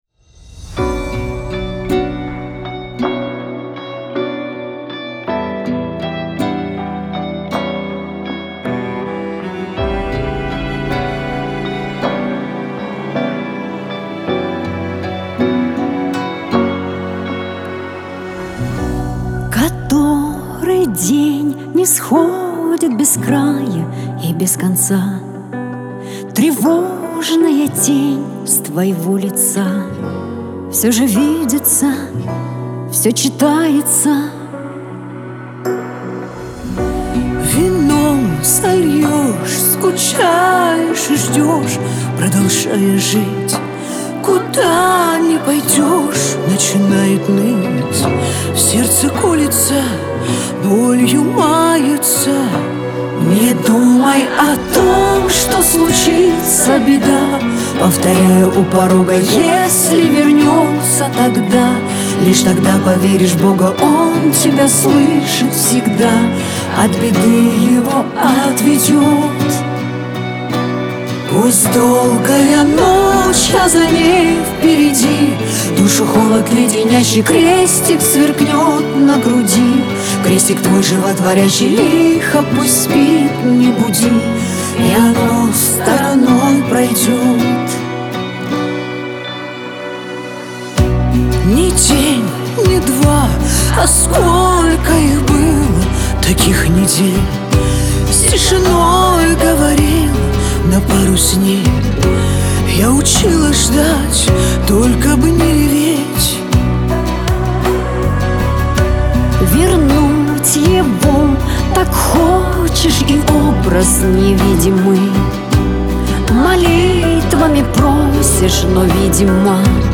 Шансон
дуэт
Лирика